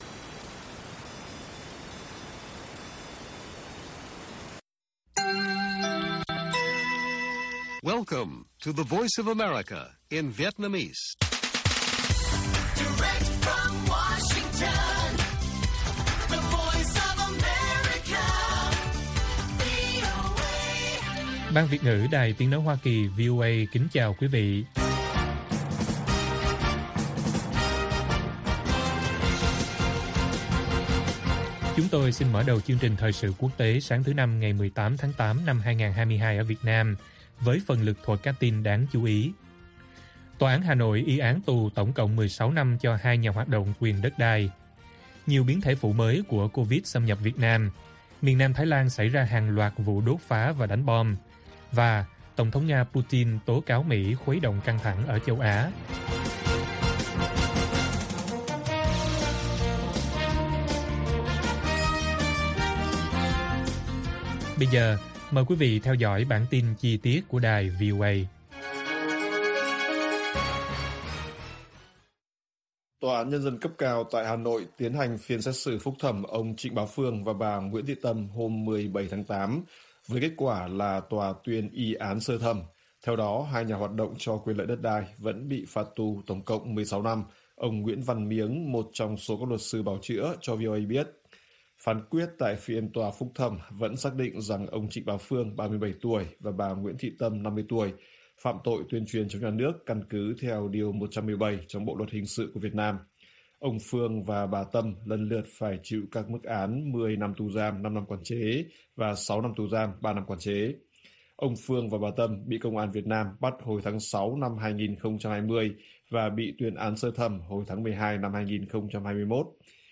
Miền nam Thái Lan xảy ra hàng loạt vụ đốt phá và đánh bom - Bản tin VOA